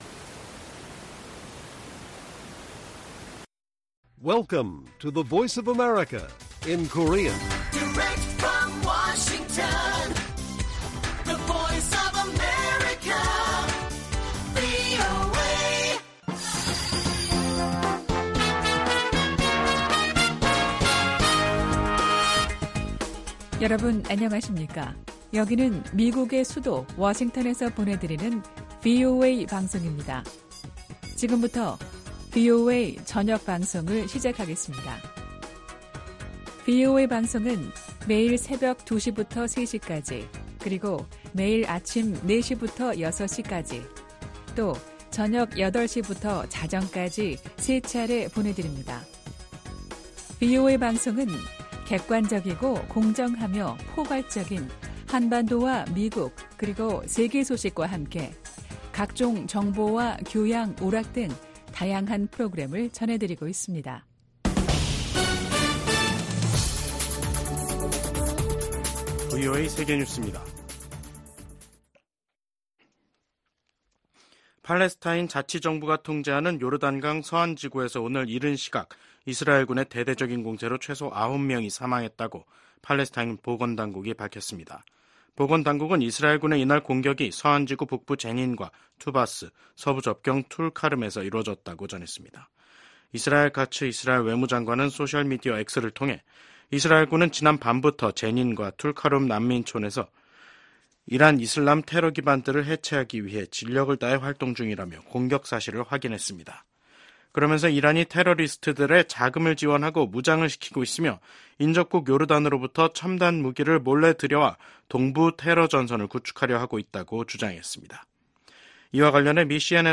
VOA 한국어 간판 뉴스 프로그램 '뉴스 투데이', 2024년 8월 28일 1부 방송입니다. 북한이 잠수함 10여 척을 국제해사기구(IMO)에 처음으로 등록했습니다.